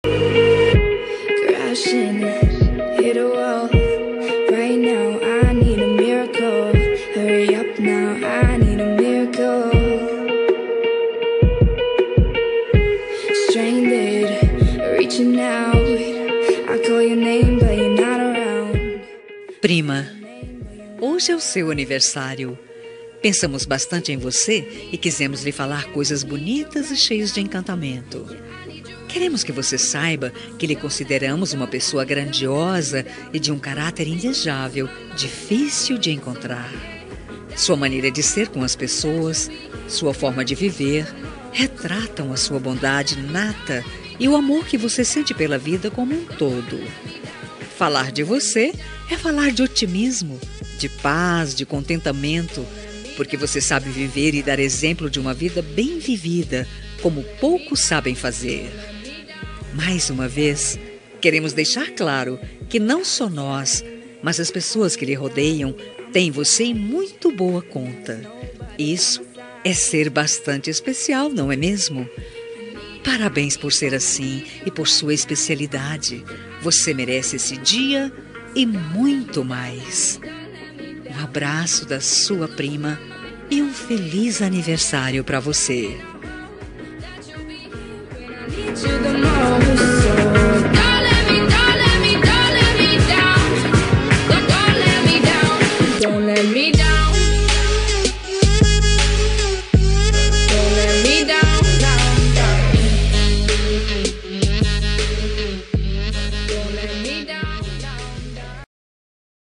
Aniversário de Prima – Voz Feminina – Cód: 042801